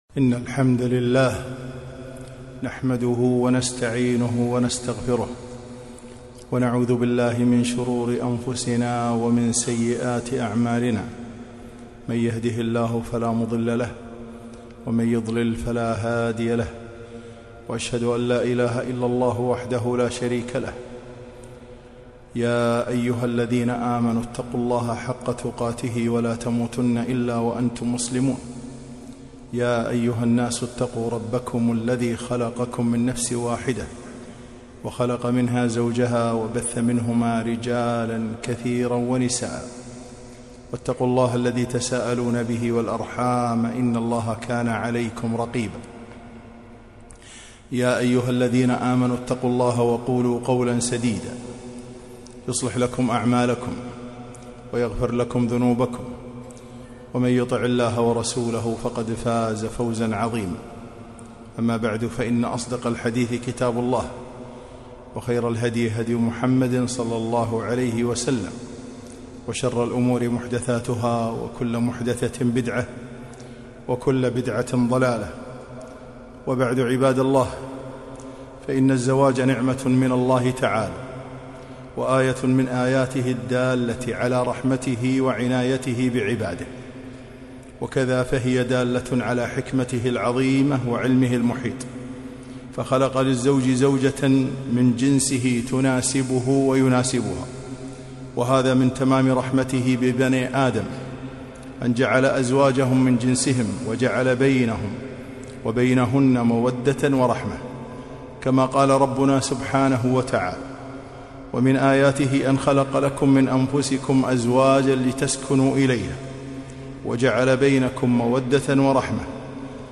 خطبة - المحافظة على بيت الزوجية